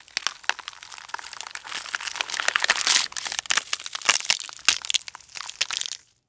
Flesh Rip Sound
horror